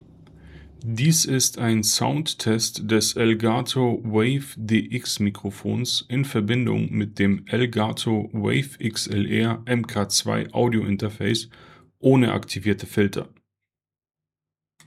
Soundtest
Softwareseitig ist die Lautstärke auf 60 % eingestellt und die Aufnahmedistanz beträgt etwa 20 Zentimeter.
Test 3: Elgato WAVE XLR MK.2 ohne Filter